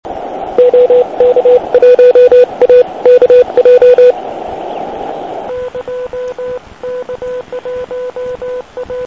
Ovšem poslech v místě, kde není široko daleko žádné elektrické vedení - to je zážitek. A jak jsem některé z Vás slyšel?